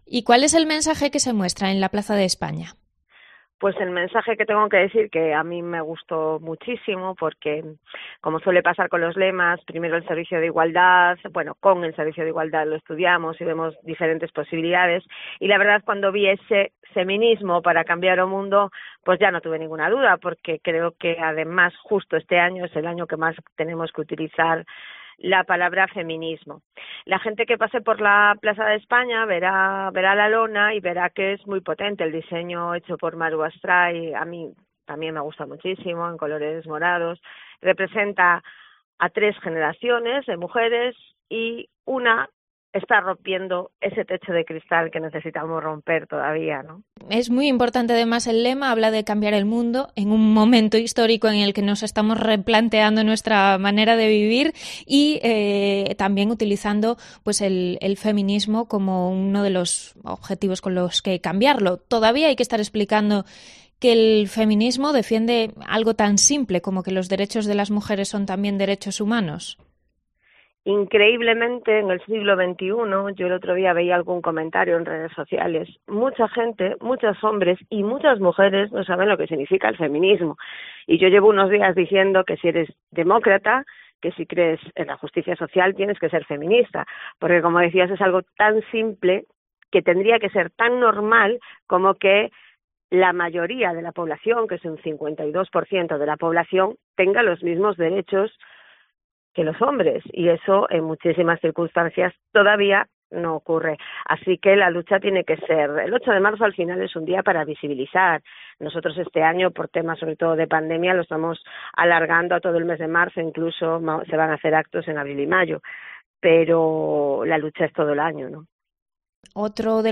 Entrevista a la concejala de Igualdad de Pontevedra, Yoya Blanco, en la previa del 8 de marzo